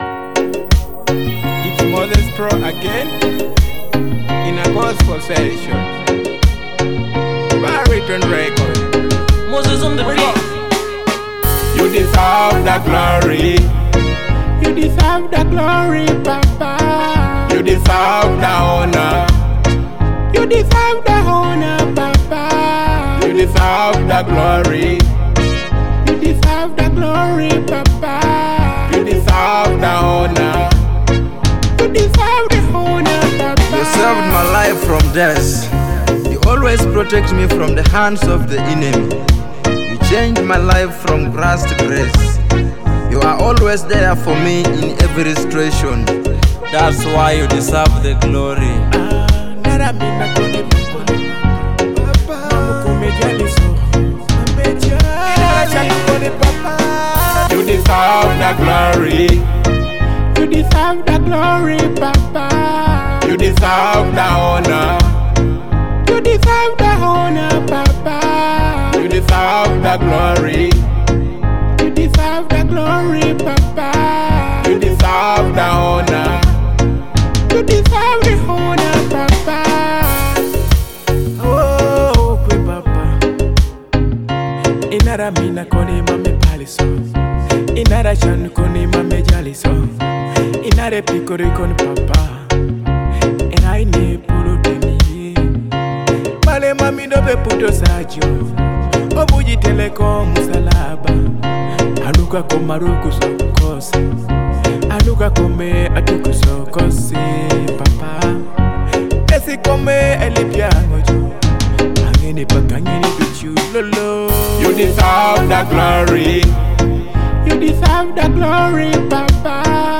heartfelt gospel worship song